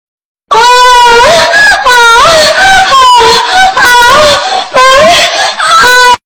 PLAY ahhhhhhhhhh sound
ahhhhhhhh.mp3